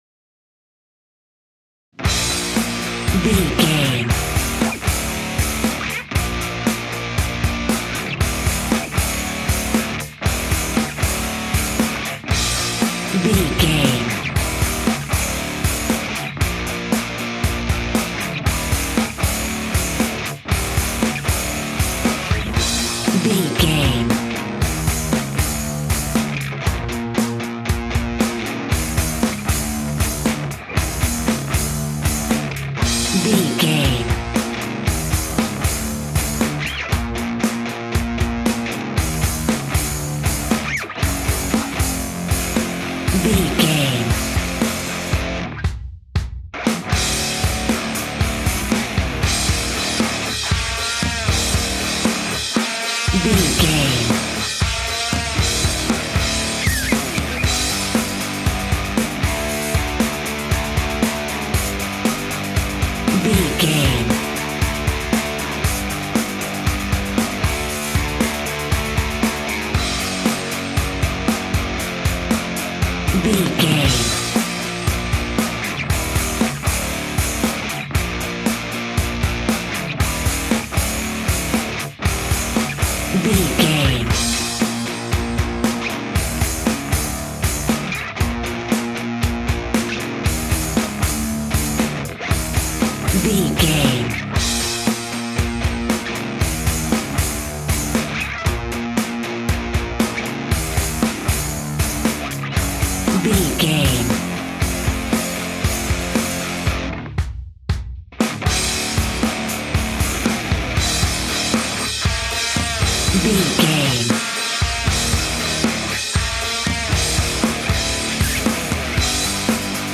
Epic / Action
Ionian/Major
B♭
hard rock
blues rock
Rock Bass
heavy drums
distorted guitars
hammond organ